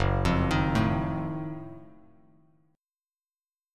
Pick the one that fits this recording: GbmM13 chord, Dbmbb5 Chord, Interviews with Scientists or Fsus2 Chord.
GbmM13 chord